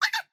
sounds / mob / fox / idle1.ogg